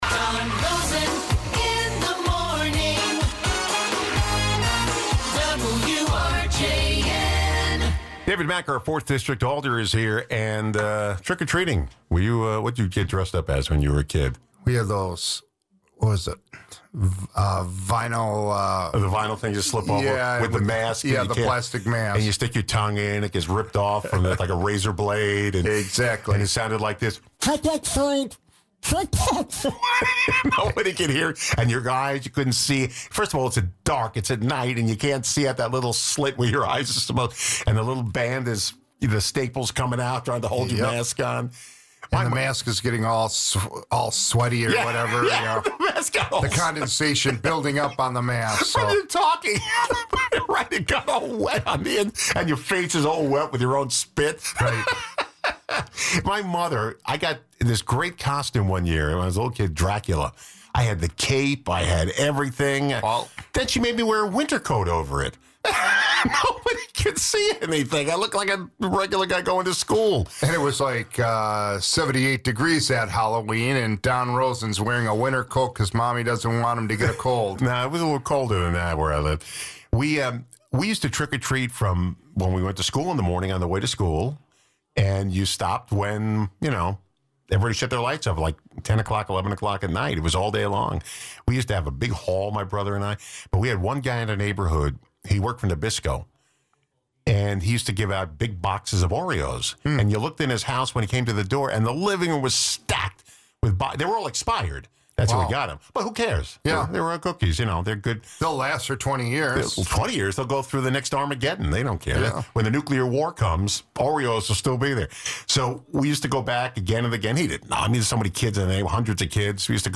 City of Racine Alder David Maack stops by to discuss the Racine city budget and other lighter topics.